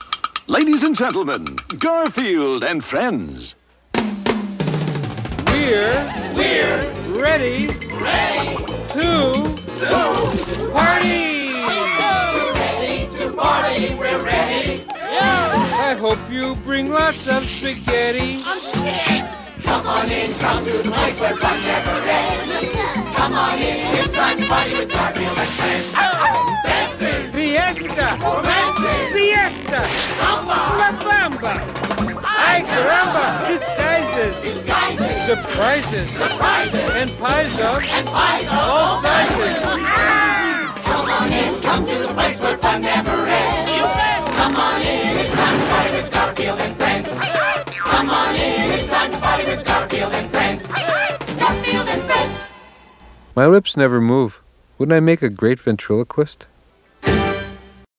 BPM135-135
Audio QualityCut From Video